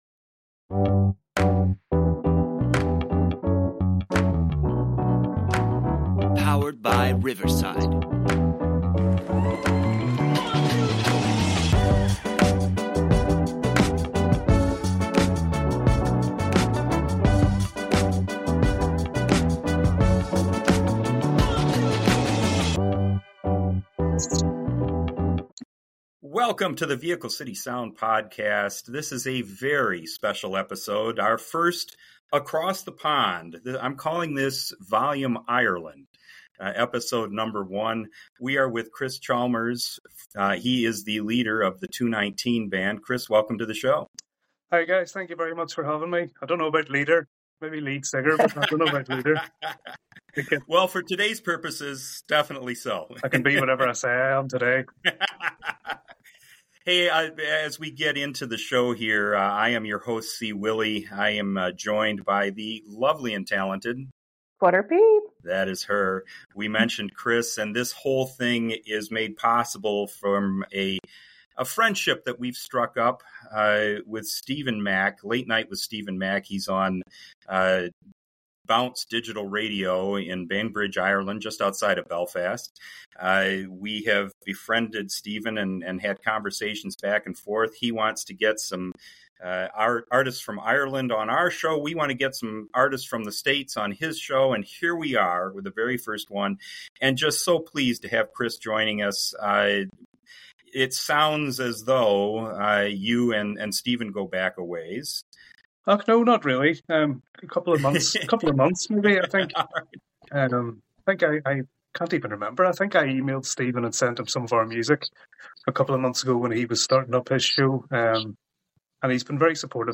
Chicago style blues will draw you in
you'll find the vocals and harmonies to be top shelf!